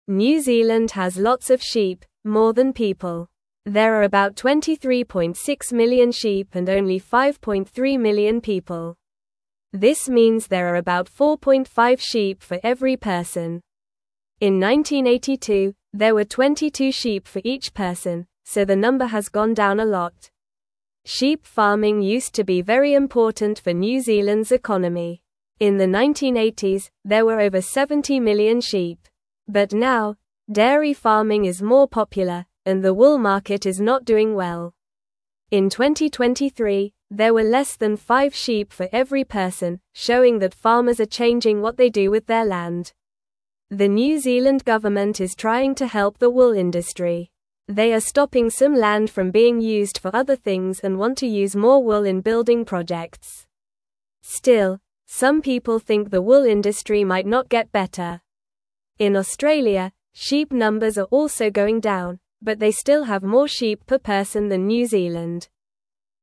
Normal
English-Newsroom-Lower-Intermediate-NORMAL-Reading-New-Zealands-Sheep-Numbers-Are-Getting-Smaller.mp3